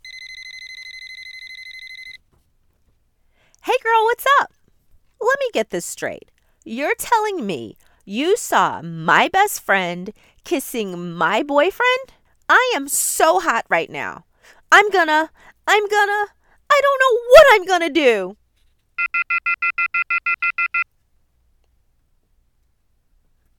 Animation
Female-Teen-Animation.mp3